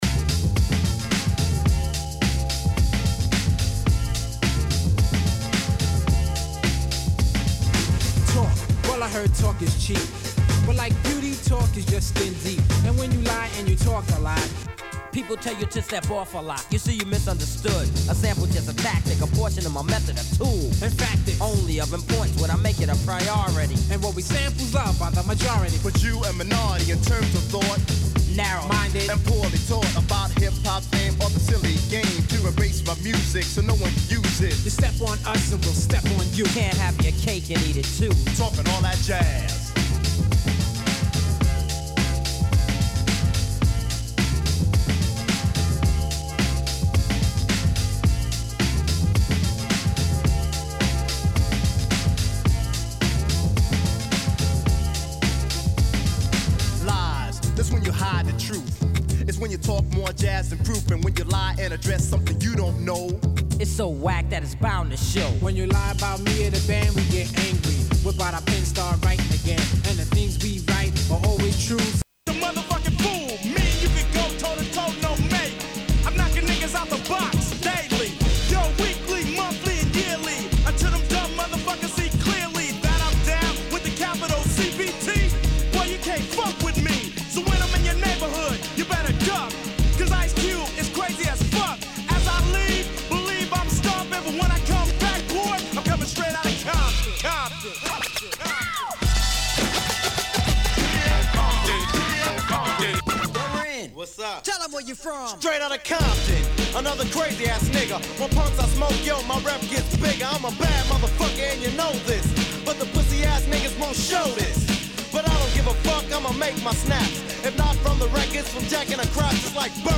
⌂ > Vinyly > Hiphop-Breakbeat >